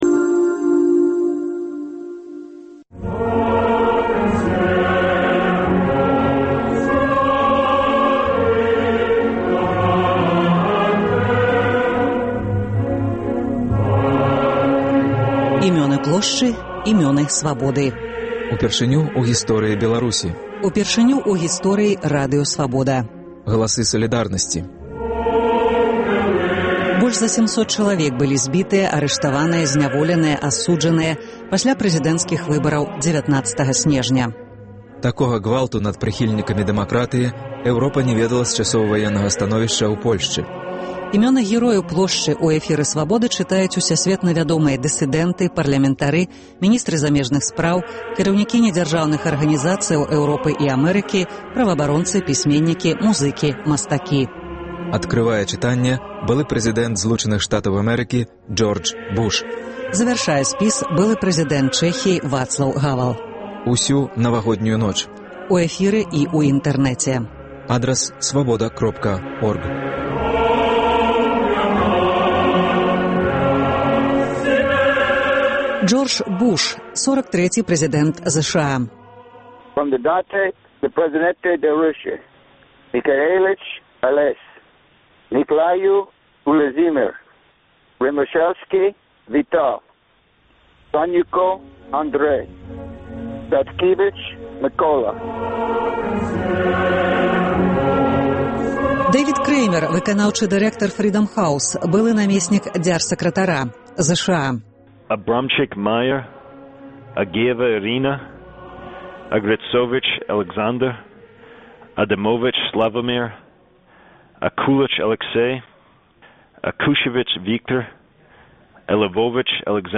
Імёны герояў Плошчы ў эфіры Свабоды чытаюць усясьветна вядомыя дысыдэнты, парлямэнтары, міністры замежных справаў, кіраўнікі недзяржаўных арганізацыяў Эўропы і Амэрыкі, праваабаронцы, пісьменьнікі, музыкі, мастакі. Адкрывае чытаньне былы прэзыдэнт Злучаных Штатаў Джордж Буш. Завяршае былы прэзыдэнт Чэхіі Вацлаў Гавэл.